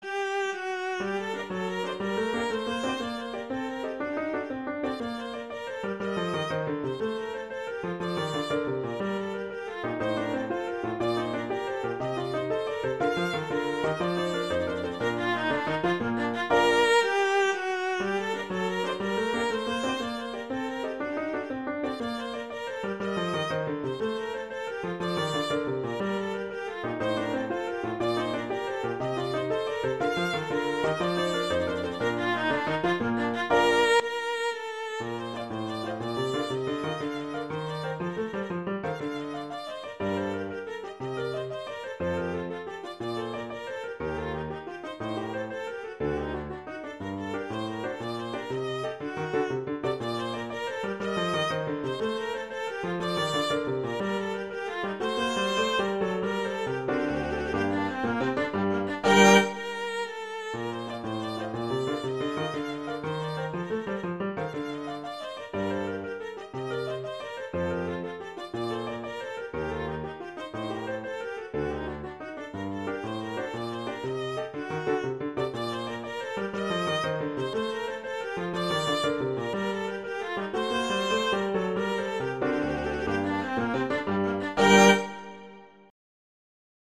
viola and piano
classical
G minor, Bb major
Allegro